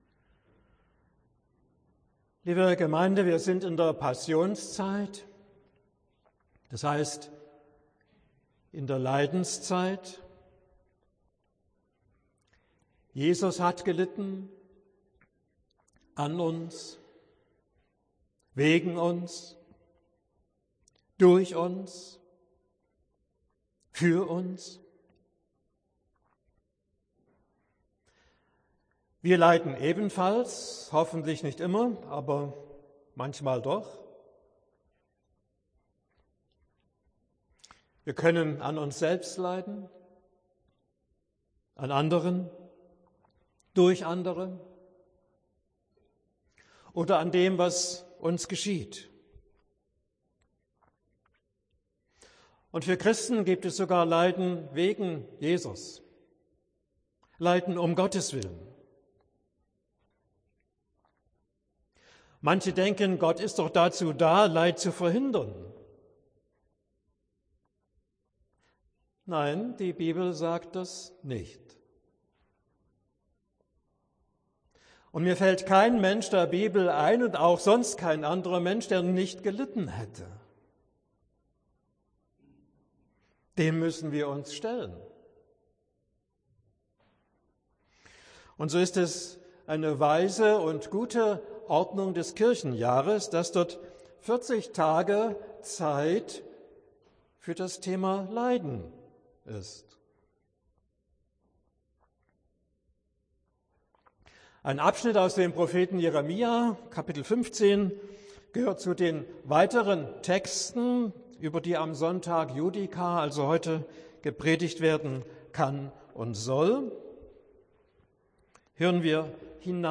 Predigt für den Sonntag Judika (für die Passionszeit)